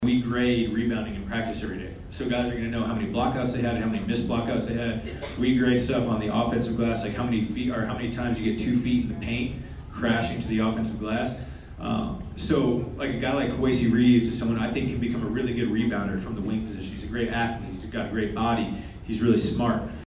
The event was hosted by the Gator Boosters and presented by the Fighting Gator Touchdown Club.
When asked how he would turn around the issue of rebounding, Coach Golden simply replied, “Coaching,” receiving loud laughter from attendees.